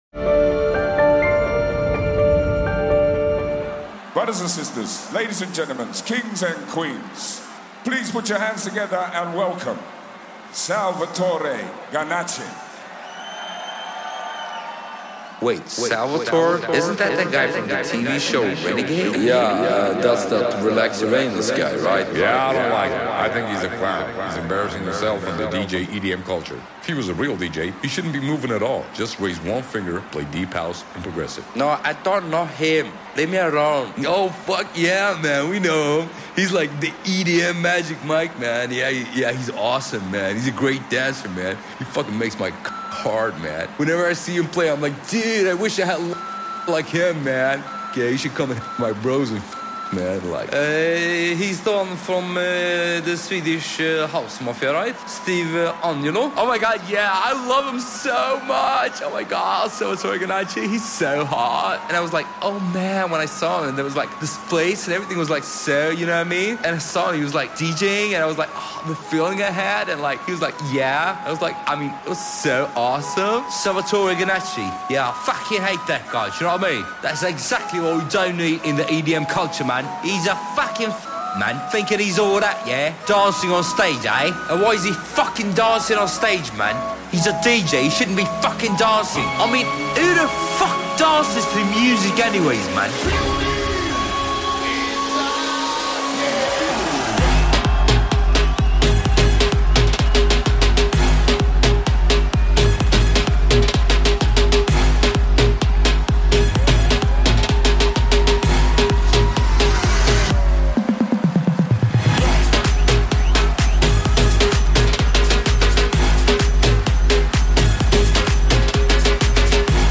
Also find other EDM Livesets, DJ Mixes and Radio